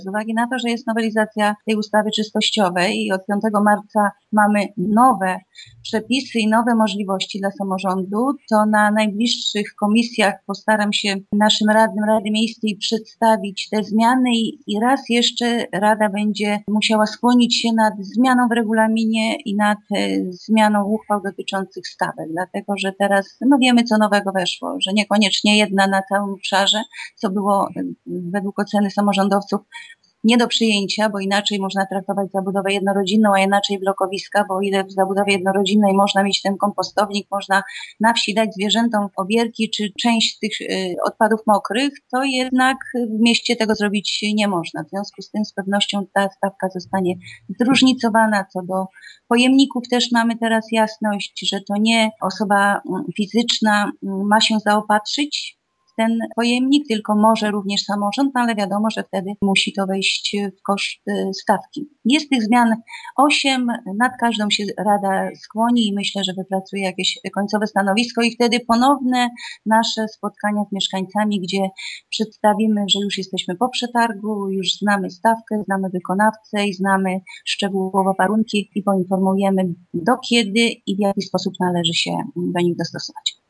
Burmistrz Zwolenia Bogusława Jaworska zastrzega, że niektóre uchwały rady miejskiej mogą się jeszcze zmienić: